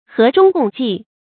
注音：ㄏㄜˊ ㄓㄨㄙ ㄍㄨㄙˋ ㄐㄧˋ
和衷共濟的讀法